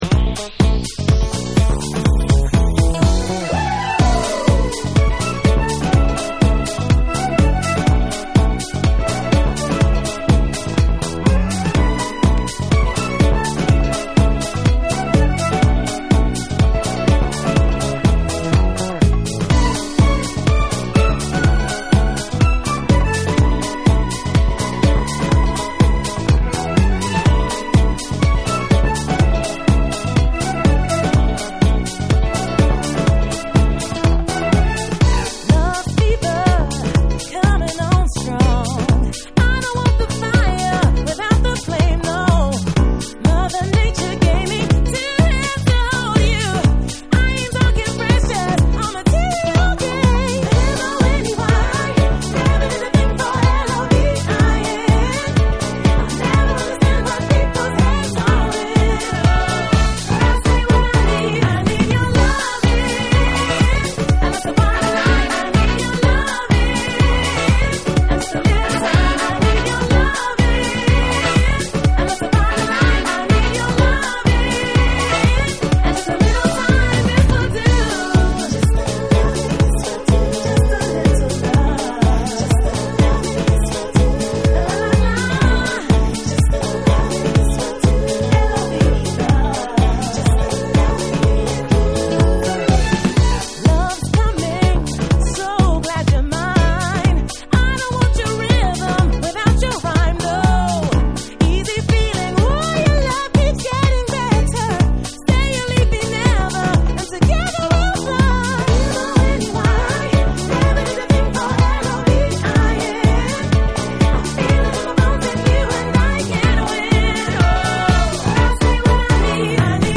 TECHNO & HOUSE / ALL 840YEN